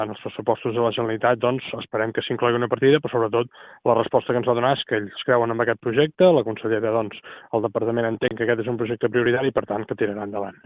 Són declaracions a Ràdio Calella TV.